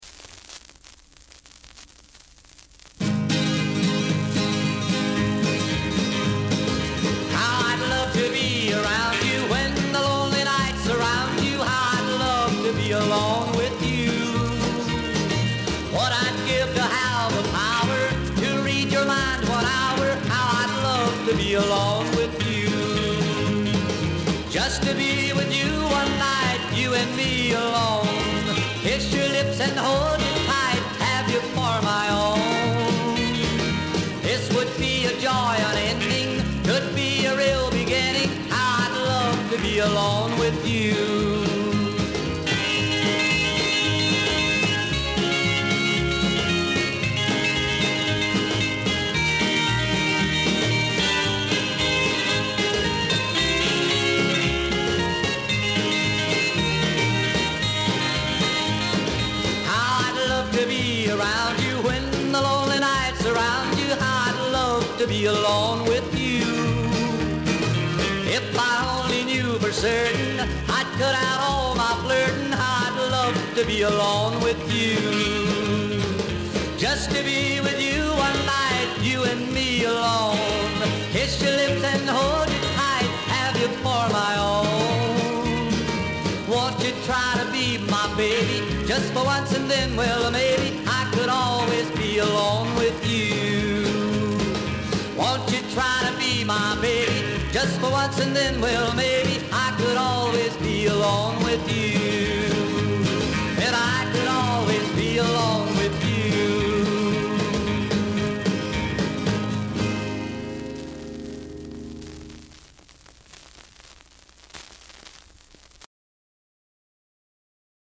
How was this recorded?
45 RPM Vinyl record